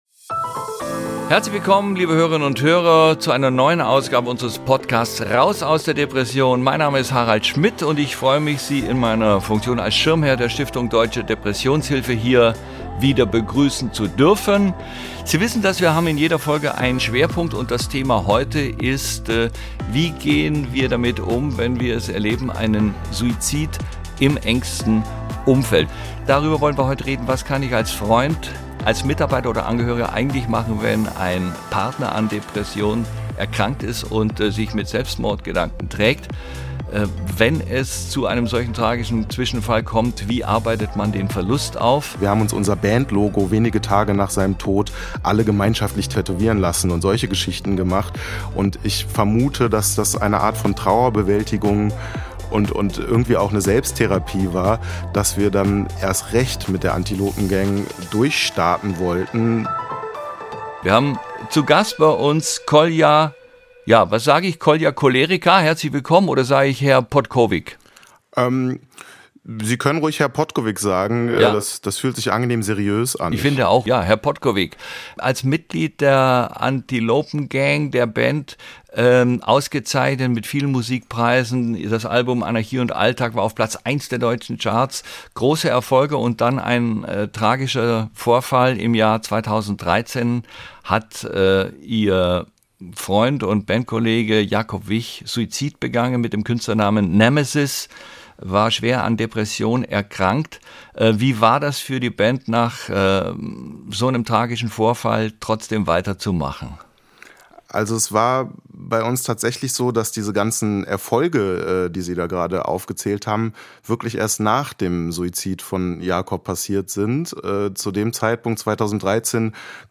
Nachrichten - 12.06.2022